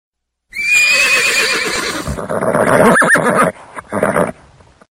Pets And Animals Ringtones